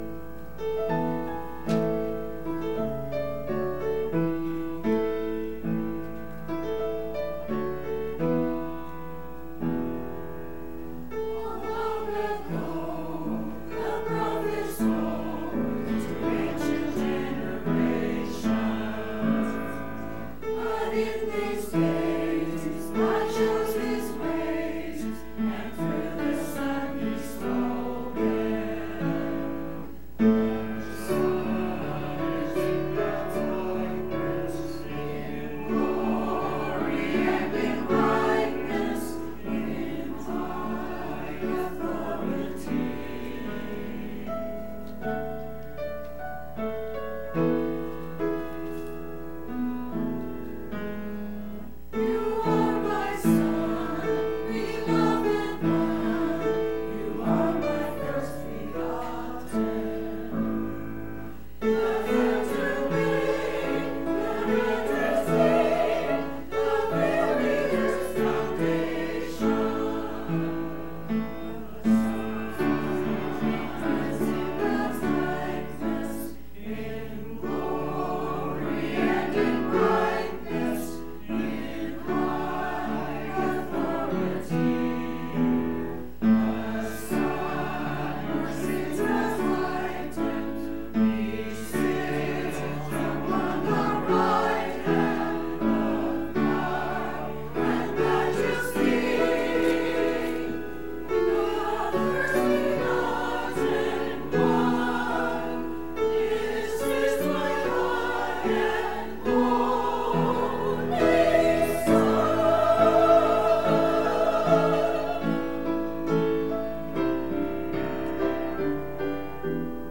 To hear the church choir praise God with music please click below.